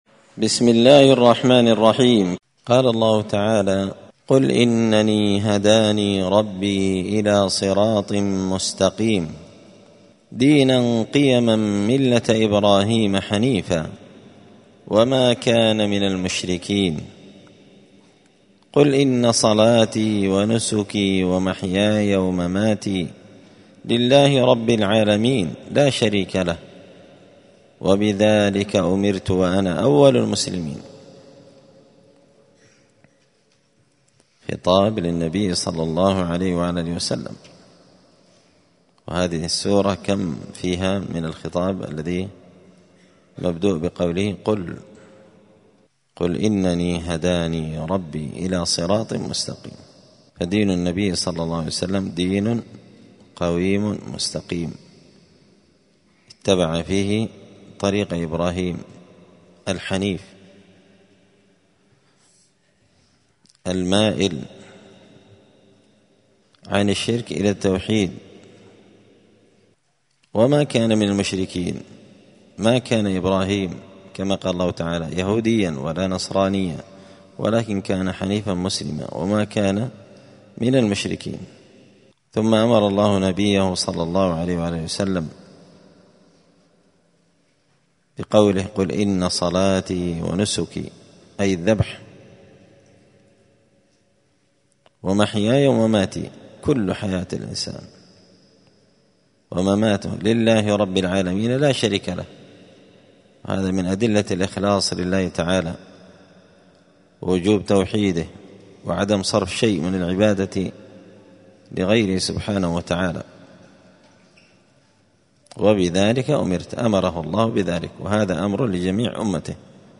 📌الدروس اليومية
دار الحديث السلفية بمسجد الفرقان بقشن المهرة اليمن